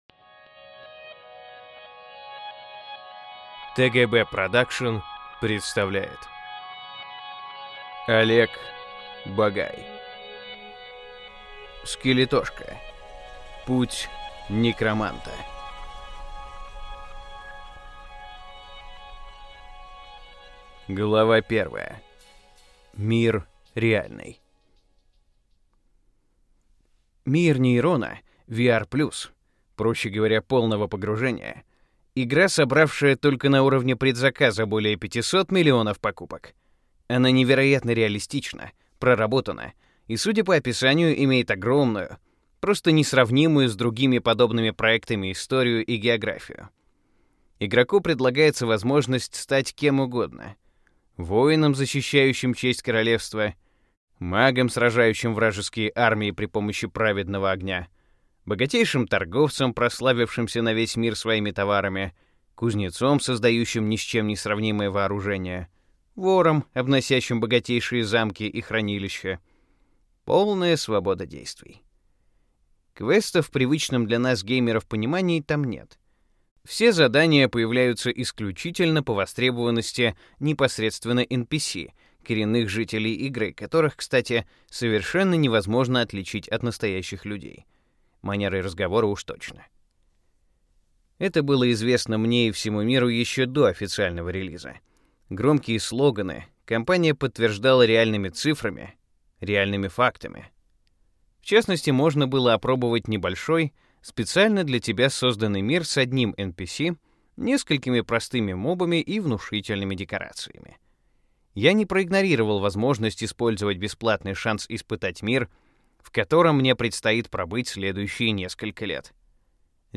Аудиокнига Скелетошка: Путь некроманта | Библиотека аудиокниг